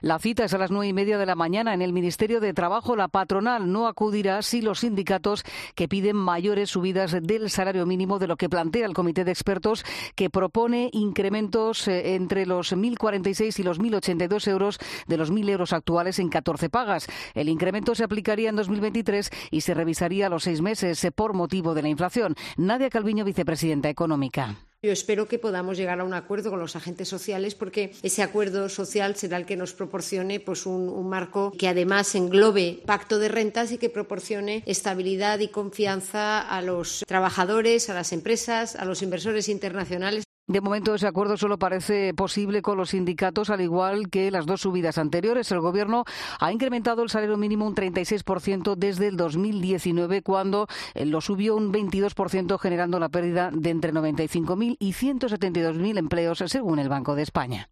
Primera reunión para negociar una nueva subida del SMI, informa